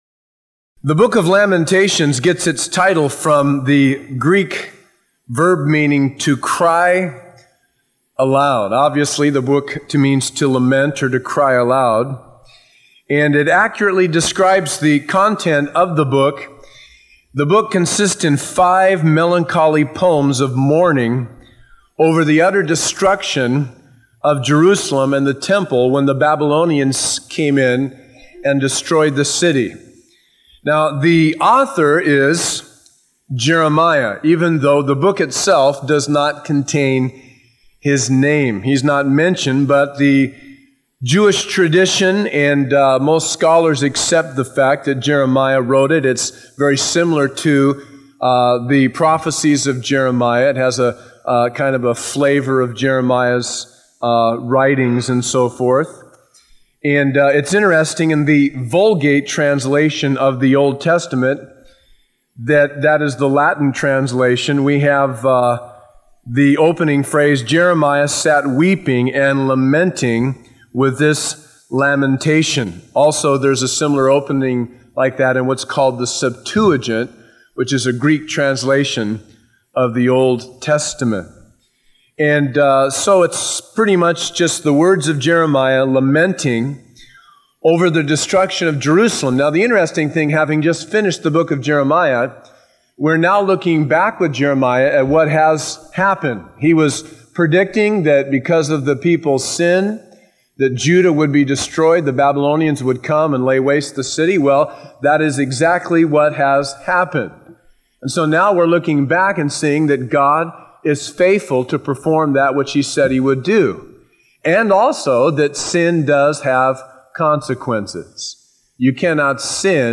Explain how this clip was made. taught at Calvary Chapel San Bernardino from June 1996.